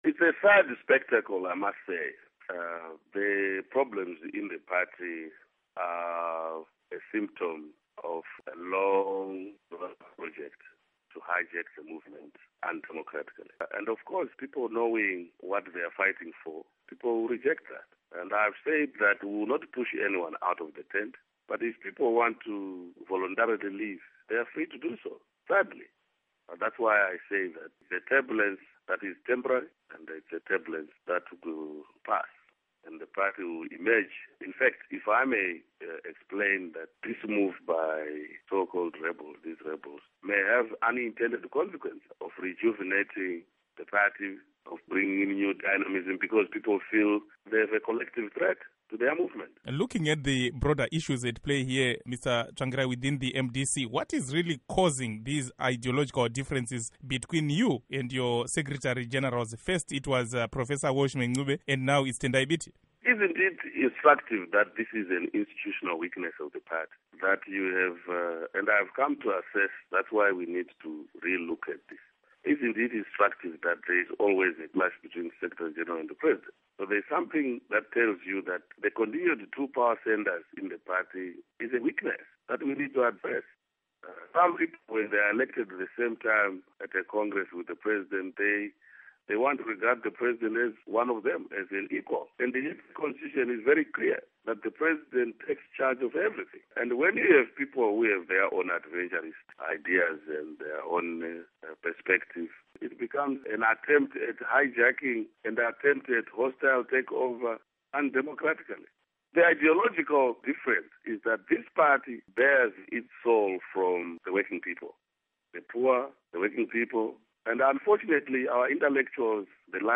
Exclusive Interview: Morgan Tsvangirai